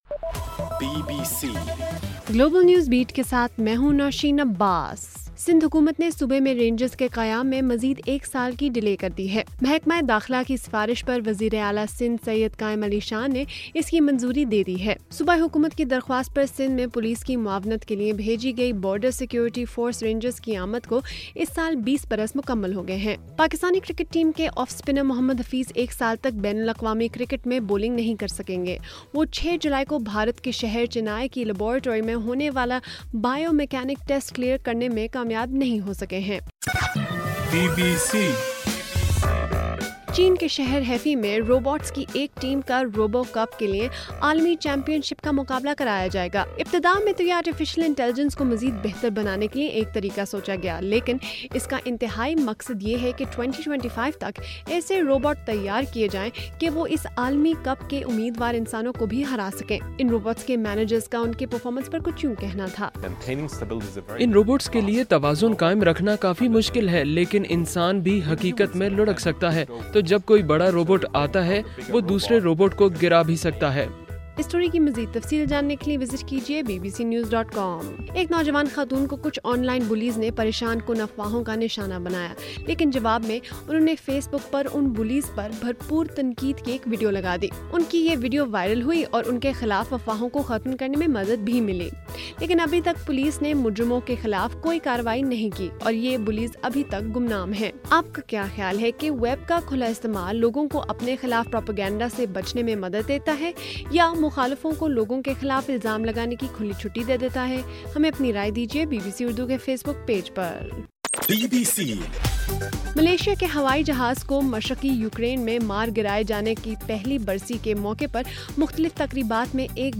جولائی 17: رات 10 بجے کا گلوبل نیوز بیٹ بُلیٹن